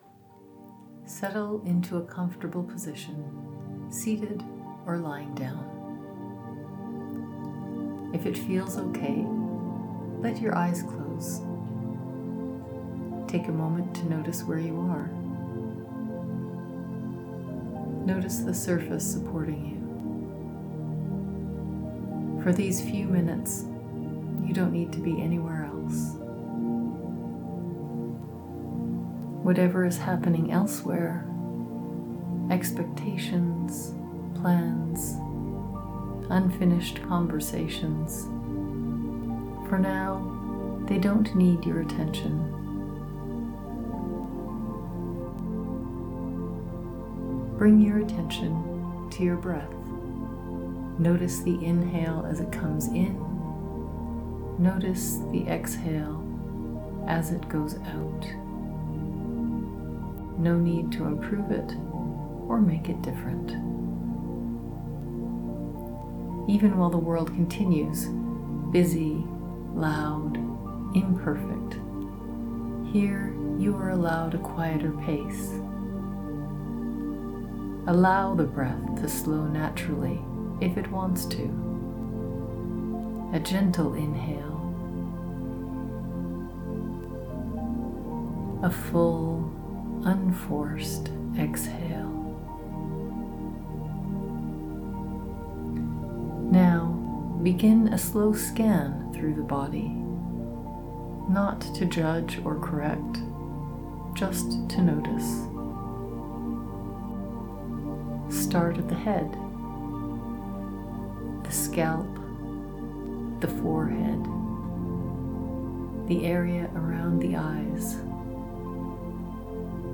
A Free Guided Body Scan to Support Relaxation Before or After Massage
I’ve put together a free, short, guided 6½-minute breathing and body scan you can use before or after an in-home massage — or anytime you need a quick reset.